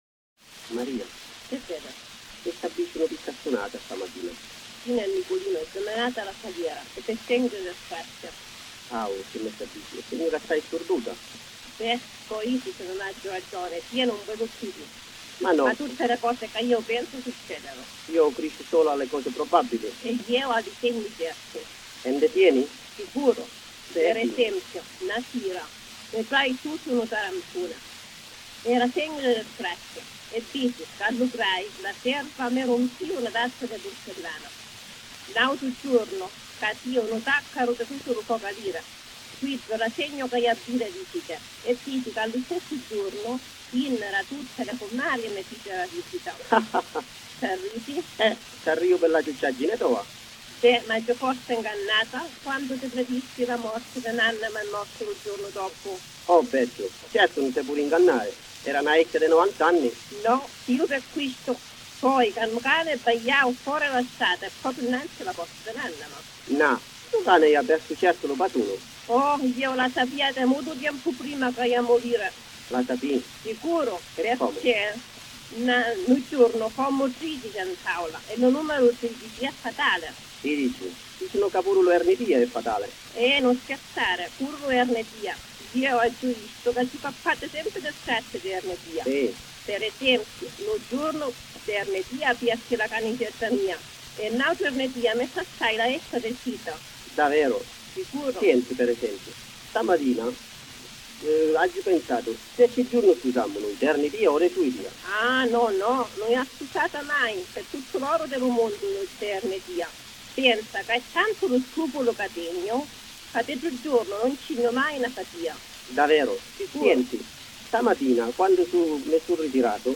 Il contenuto dei due lati può essere comodamente ascoltato, anche se il fruscio di fondo e ancor più i limiti tecnici della registrazione di allora lo rendono non integralmente intelligibile.